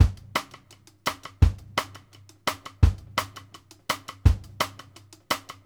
Index of /90_sSampleCDs/Sampleheads - New York City Drumworks VOL-1/Partition F/SP REGGAE 84
BEAT      -L.wav